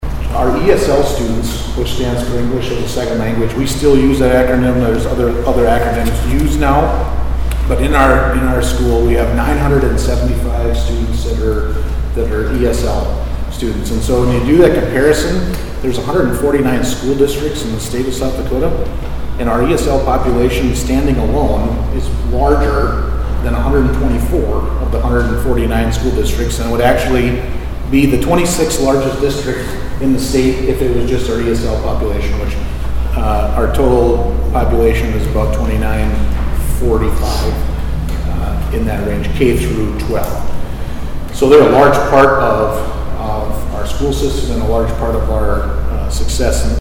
Americans for Prosperity hosted a lunch Tuesday in Huron at Top Floor Events.